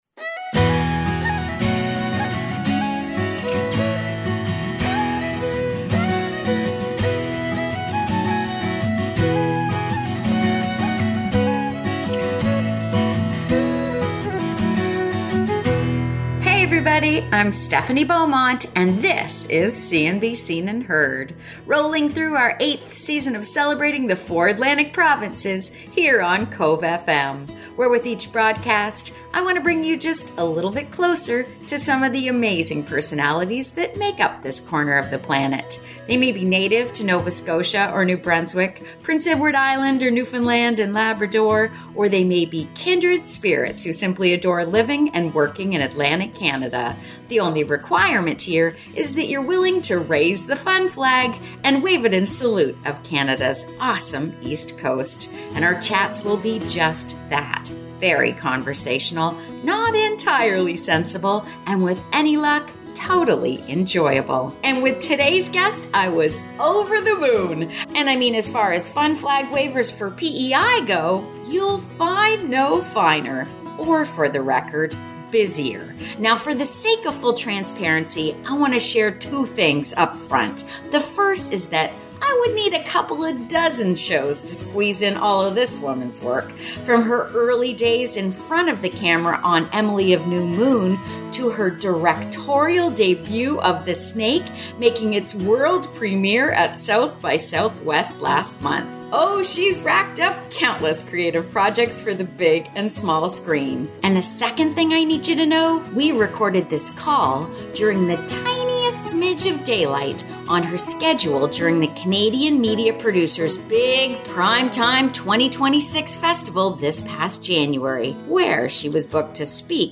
Our chats will be just that very conversational, not entirely sensible and with any luck totally enjoyable.